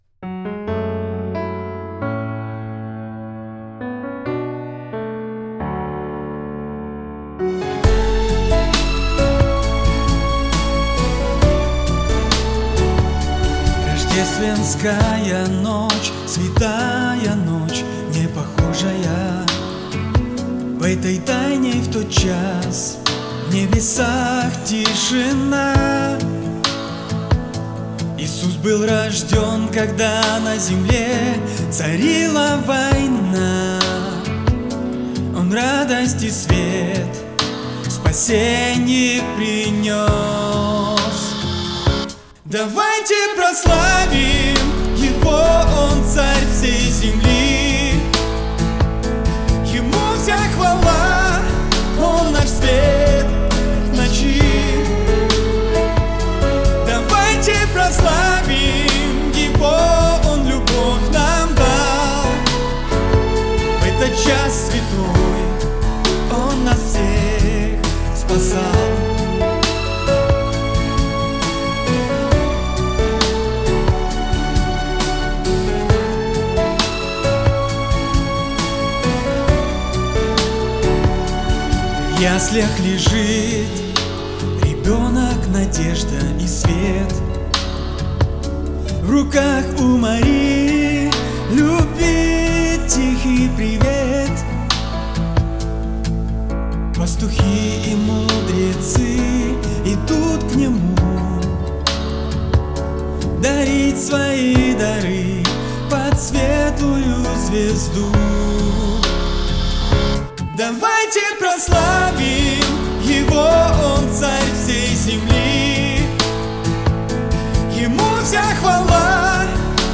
124 просмотра 330 прослушиваний 7 скачиваний BPM: 134